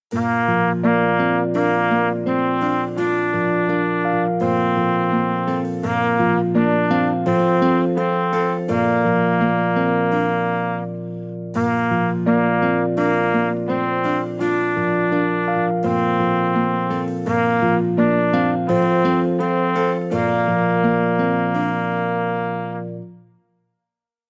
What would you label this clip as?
Voicing: Trumpet